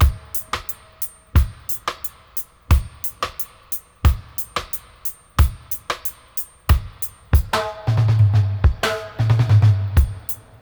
90-FX-01.wav